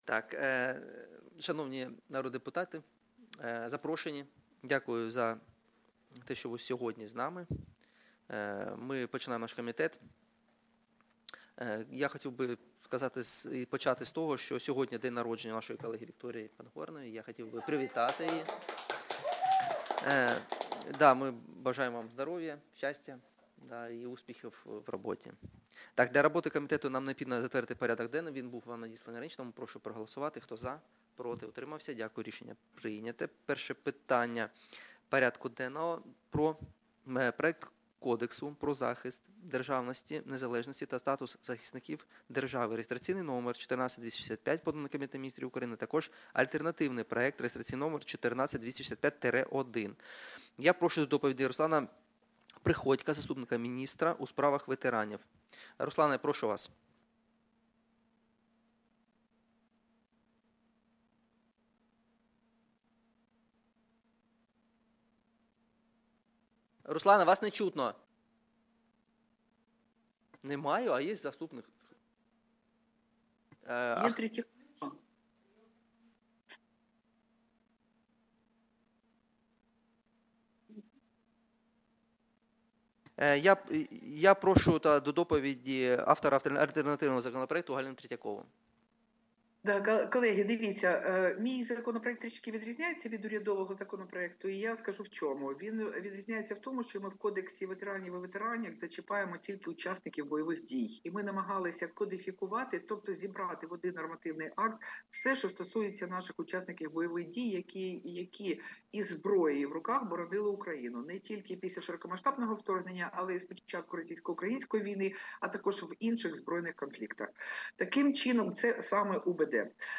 Аудіозапис засідання Комітету від 10.02.2026